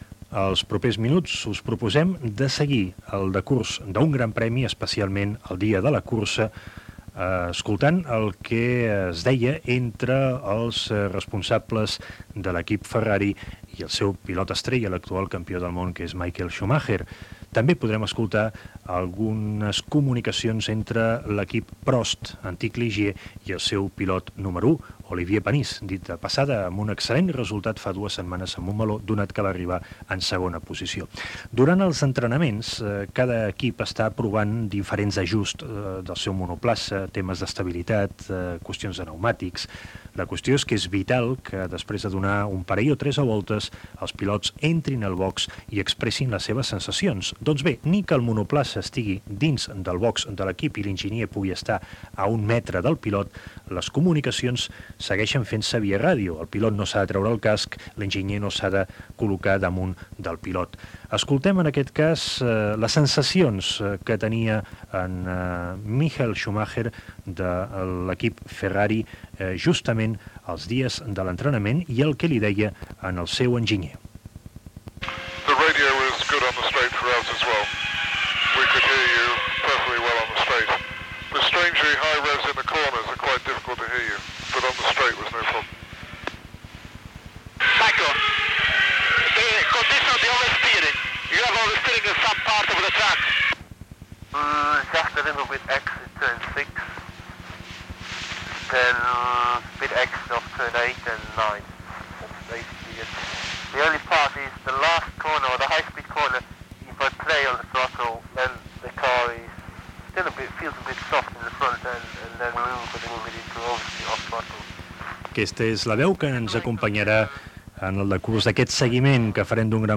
aad65d731703b2e3951f80a8db48cce7664e5ecb.mp3 Títol Ràdio 4 - L'altra ràdio Emissora Ràdio 4 Cadena RNE Titularitat Pública estatal Nom programa L'altra ràdio Descripció Comunicats via ràdio del pilot Michael Schumacher i Ros Brawn de l'equip Ferrari i del pilot Oliver Panis de l'equip Prost, al GP d'Espanya de Fórmula 1 del Circuit de Catalunya.